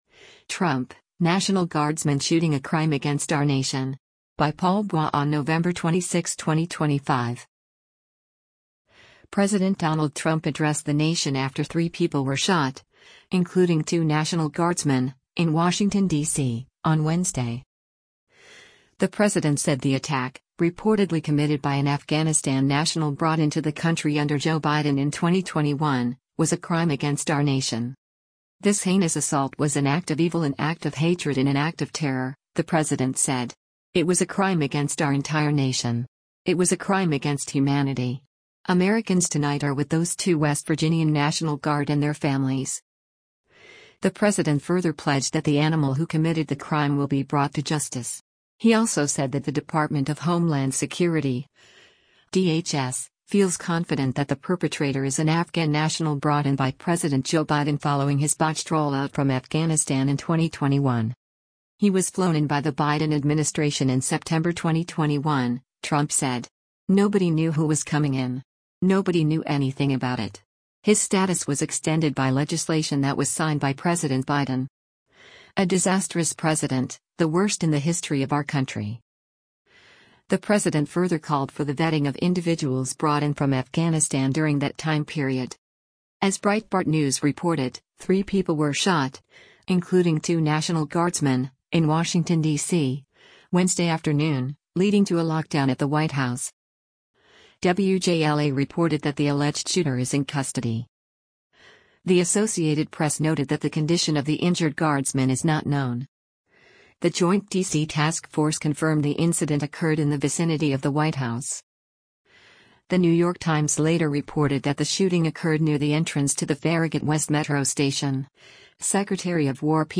President Donald Trump addressed the nation after three people were shot, including two National Guardsmen, in Washington, D.C., on Wednesday.